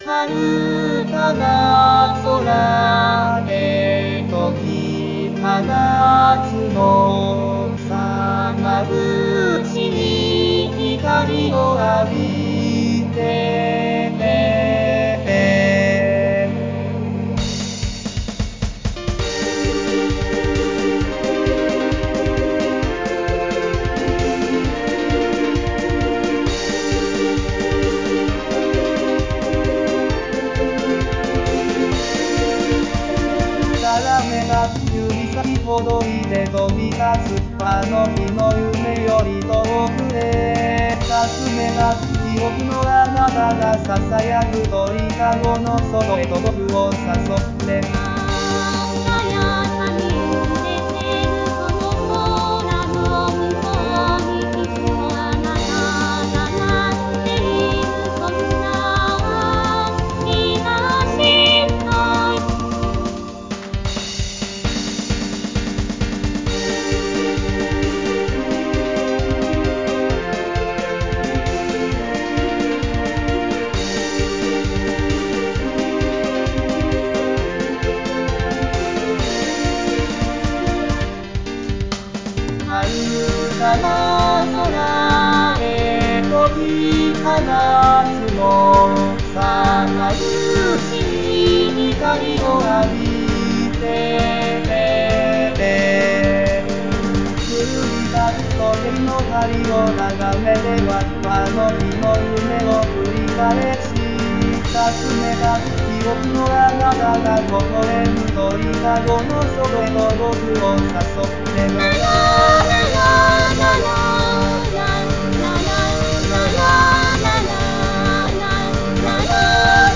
JPOP(arranged)